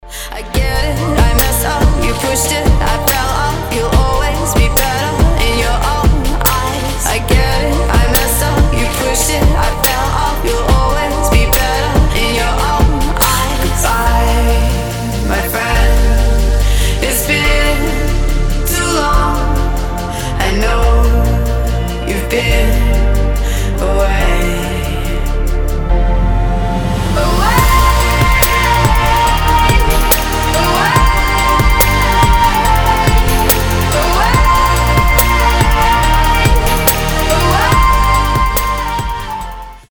громкие
dance
Electronic
красивый женский голос
future bass